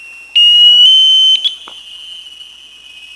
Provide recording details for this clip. SONAR Source Levels - Haro Strait - April 23, 2003